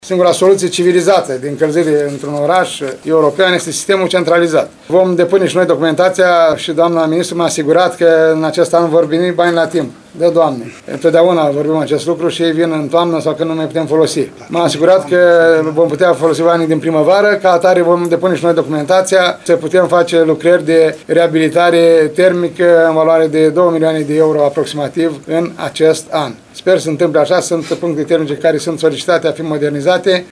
Primarul ION LUNGU a declarat că lucrările vor fi executate nu numai pe traseele de conducte, ci și la punctele termice.